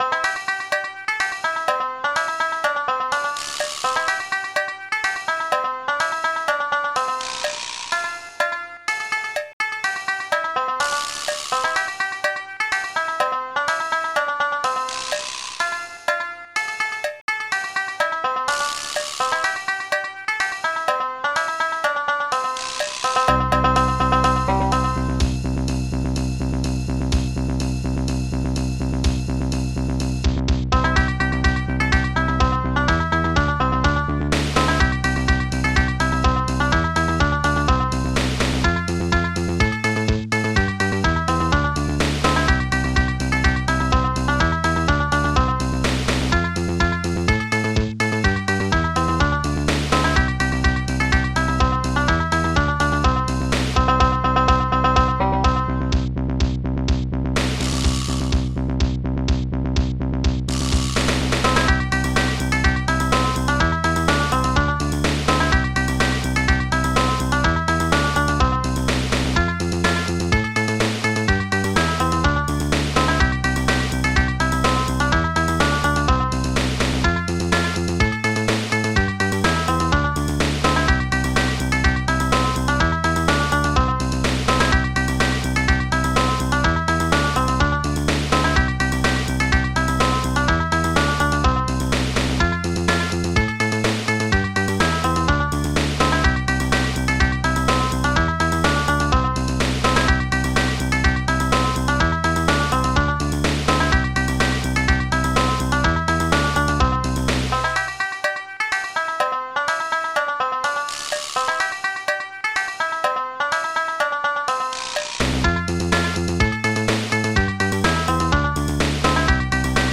Protracker Module
st-02:banjo st-05:snake st-05:ridecymbal1 st-05:cowbell3 st-03:bassguitar9 st-05:bassdrum12 st-05:snare14